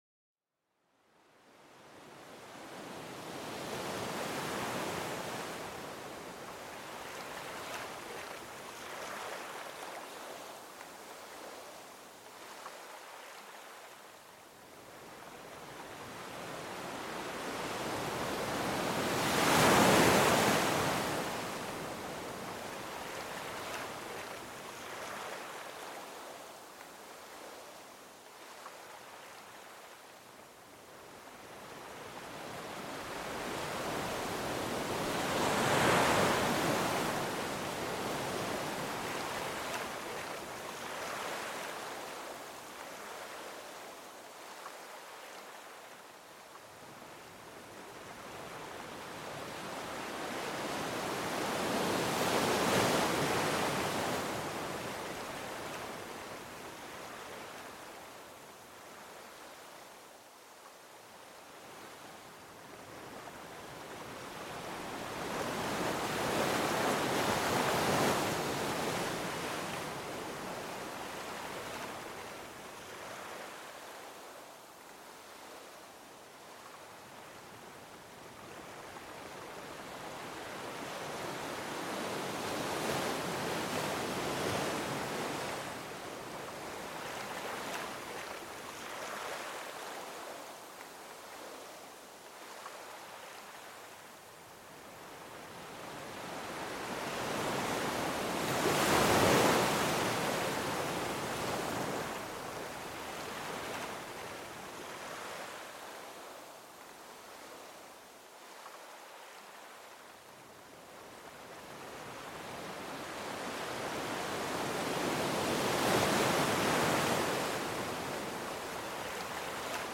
Sumérgete en el mundo sonoro de las olas del océano, un espectáculo natural de belleza calmante. Déjate mecer por el ritmo regular y armonioso de las olas chocando suavemente contra la orilla.